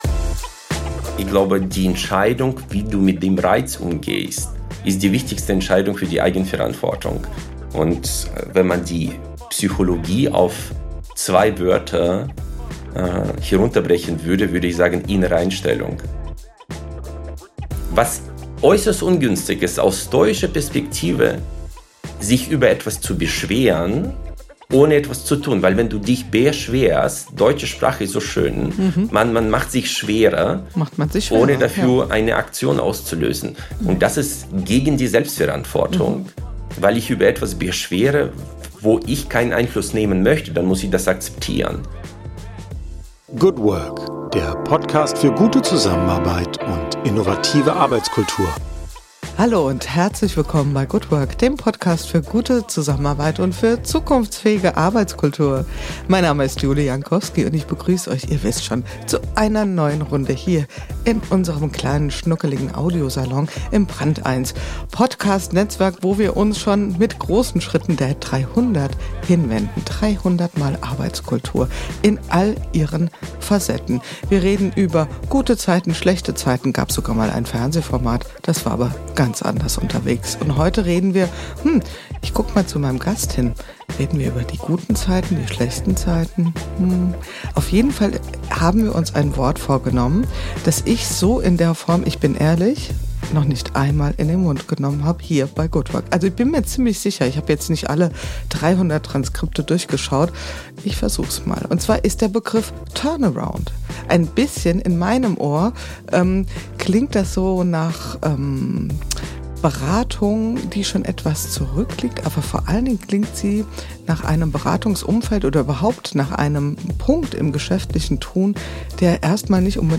Ein Gespräch über Transformation, Selbstführung und die Kunst, im richtigen Moment das Ruder herumzureißen.